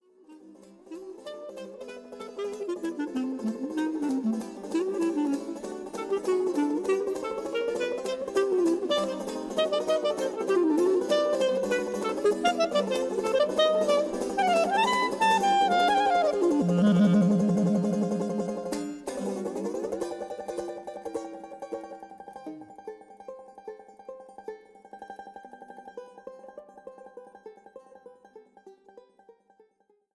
超絶技巧を駆使しあらゆる時代を駆け巡る”究極のデュオ”、
Clarinet & Bass Clarinet &
Curved Soprano Saxophone
Banjo